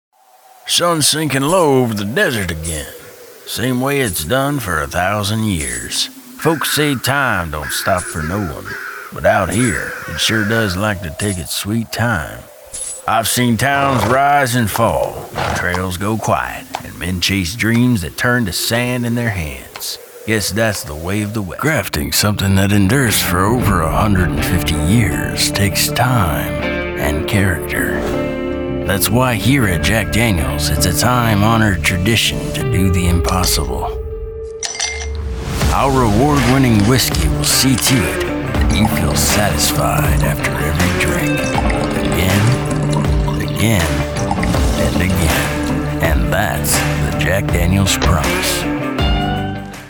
Male
Character / Cartoon
Authentic Western Voice
1201Cowboy_rustic.mp3